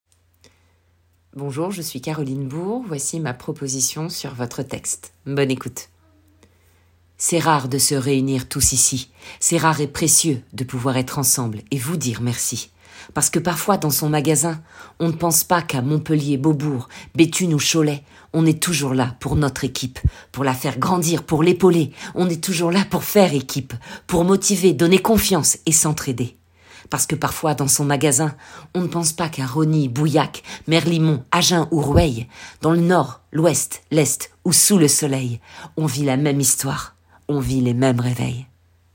SLAM
- Mezzo-soprano